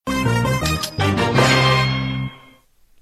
На этой странице собраны звуки из игры Brawl Stars: голоса бойцов, звуки способностей, фразы при победе и поражении.